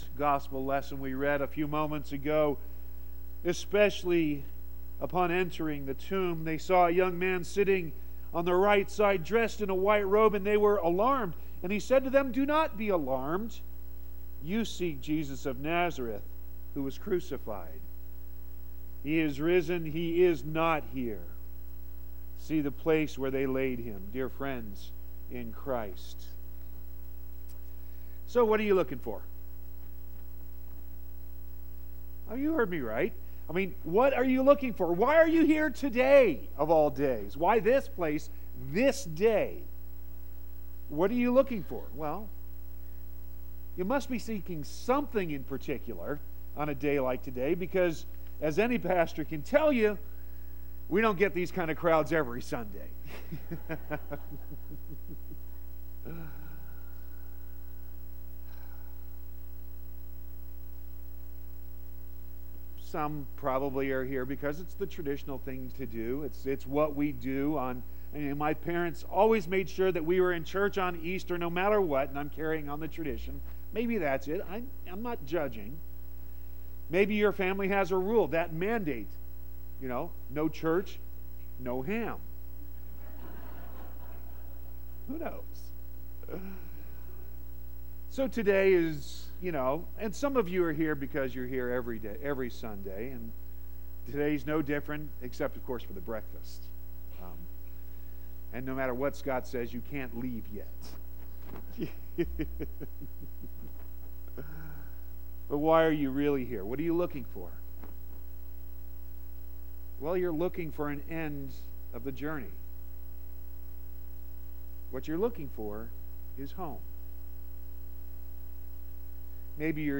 Easter Sermon 4.1.18